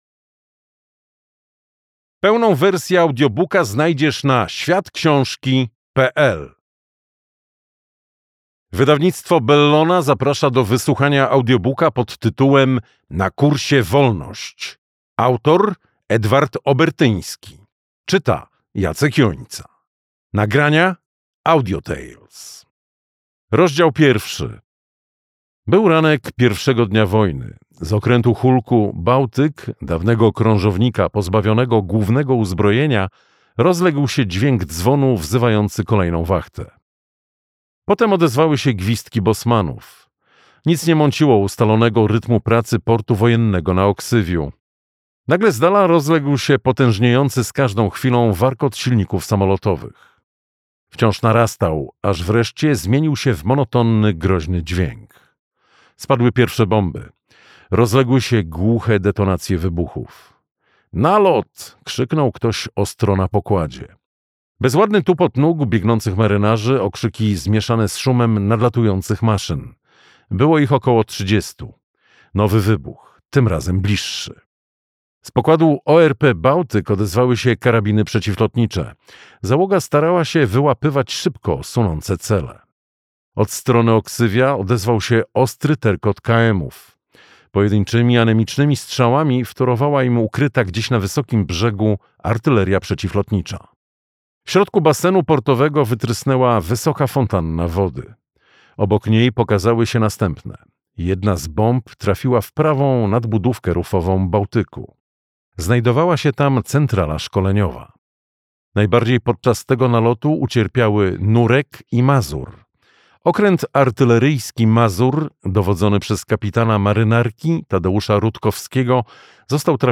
Na kursie wolność - Edward Obertyński - audiobook